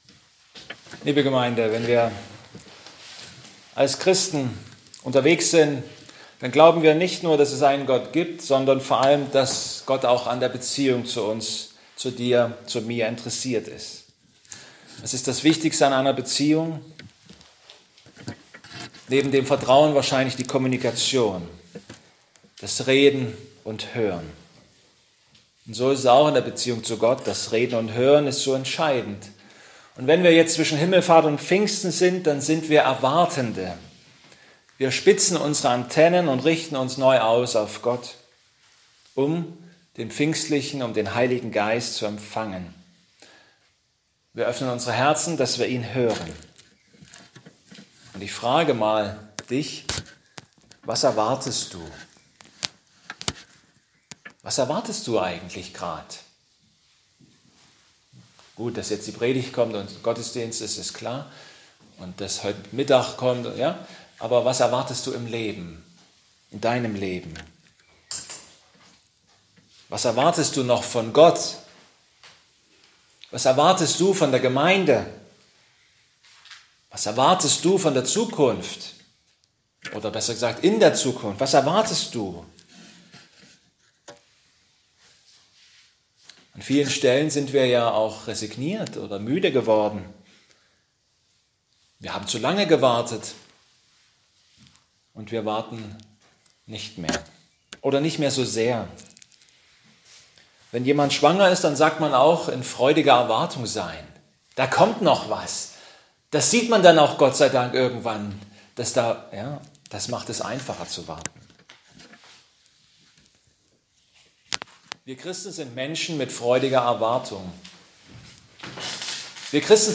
Passage: 1. Sam 3 Gottesdienstart: Predigtgottesdienst « Das Weltgericht zu Himmelfahrt Pfingsten: Lebt die Taube oder ist sie tot?